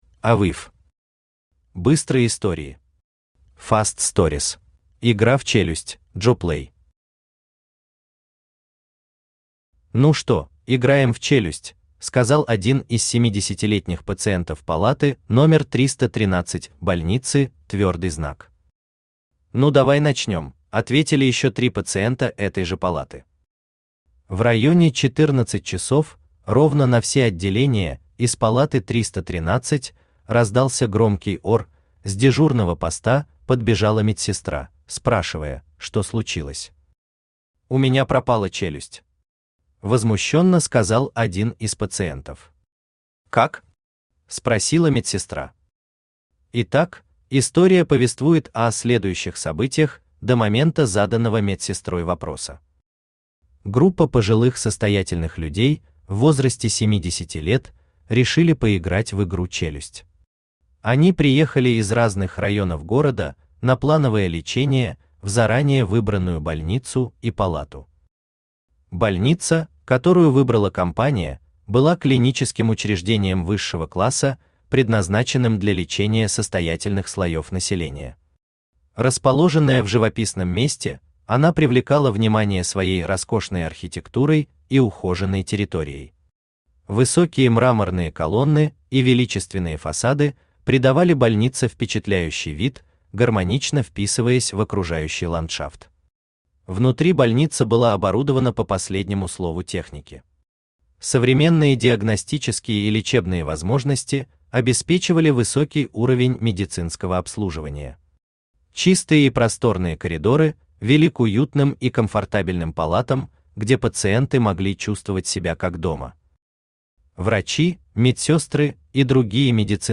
Аудиокнига Быстрые истории. Fast stories | Библиотека аудиокниг
Fast stories Автор AVIV Читает аудиокнигу Авточтец ЛитРес.